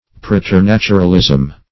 preternaturalism - definition of preternaturalism - synonyms, pronunciation, spelling from Free Dictionary
Preternaturalism \Pre`ter*nat"u*ral*ism\, n.